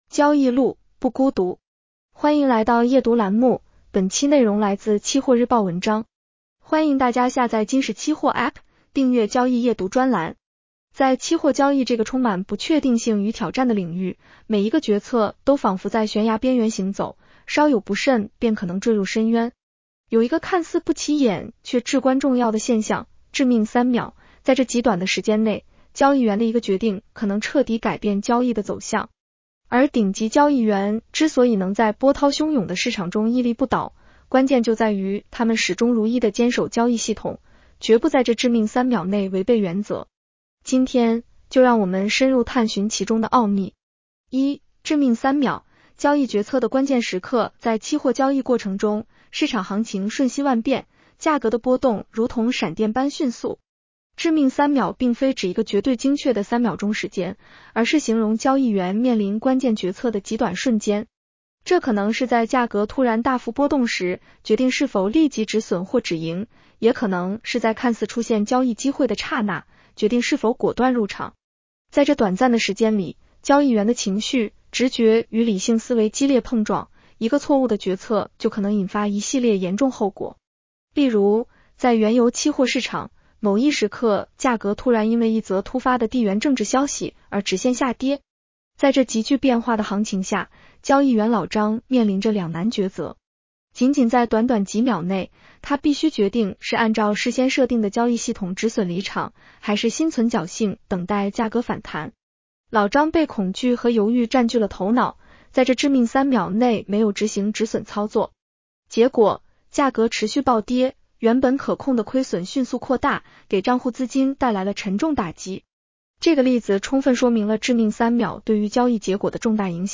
女声普通话版 下载mp3 在期货交易这个充满不确定性与挑战的领域，每一个决策都仿佛在悬崖边缘行走，稍有不慎便可能坠入深渊。